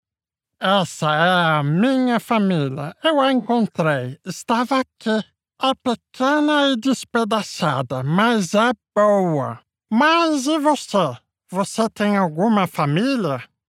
Animation
Meine hochmoderne Studioeinrichtung gewährleistet eine außergewöhnliche Klangqualität für jedes Projekt.
Perfekte Akustikkabine
Mikrofone sE Eletronics T2